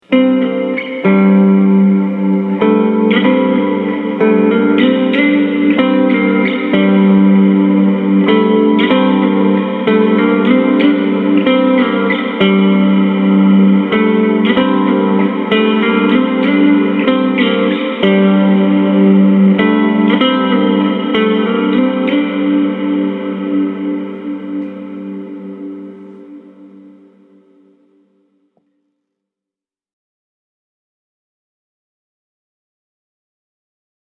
Cavernous, vast and orbital.
Major Ambience (0:34)
• Stunning, lush reverb
ambient-major.mp3